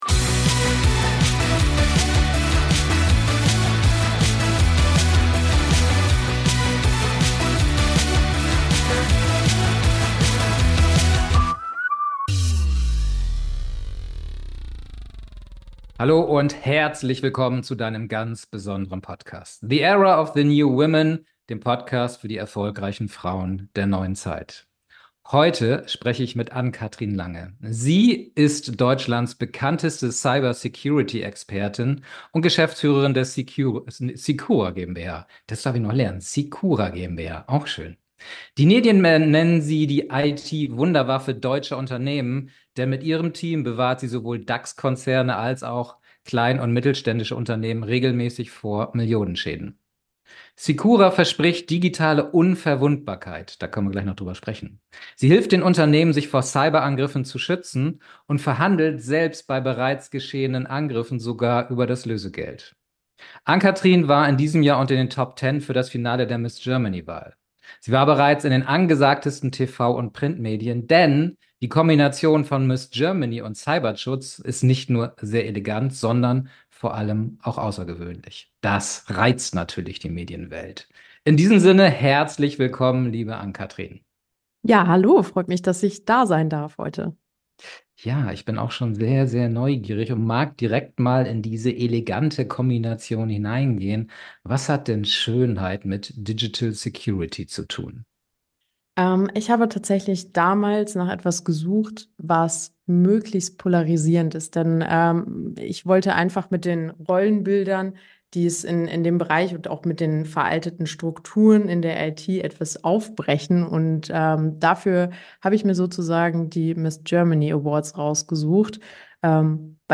#018 Die IT-Wunderwaffe deutscher Unternehmen. Interview